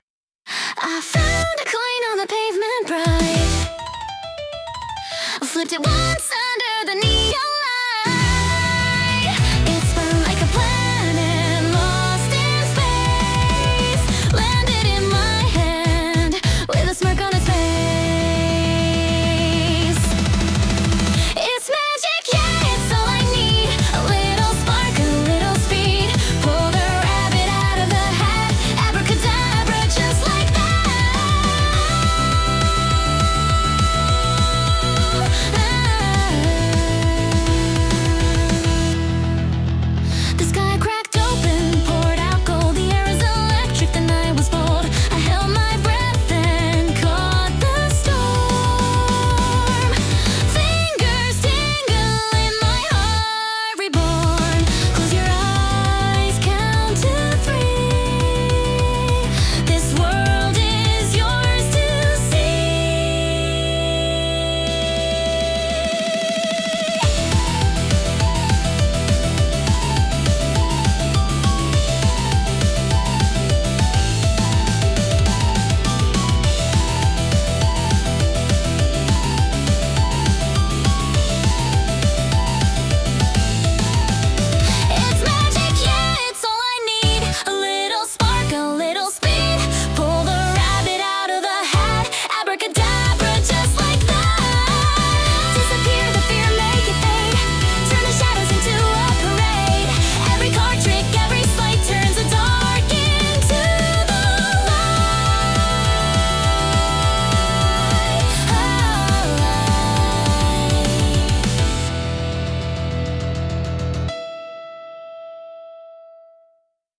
アニメ音楽は、日本のアニメ主題歌をイメージしたジャンルで、ポップでキャッチーなメロディとドラマチックな展開が特徴です。
明るくエネルギッシュな曲調から感動的なバラードまで幅広く、視聴者の感情を引き立てる要素が豊富に詰まっています。